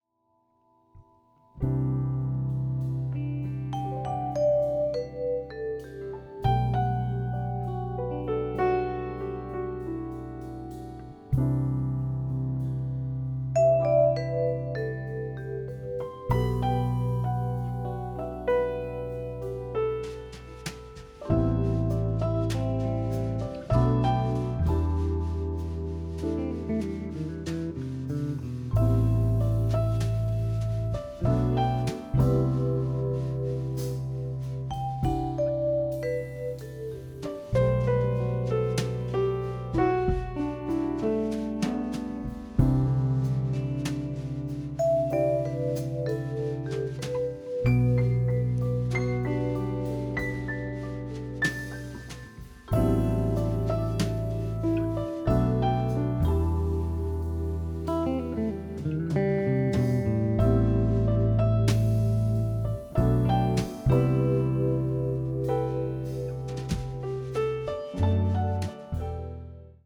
original long-form, through-composed piece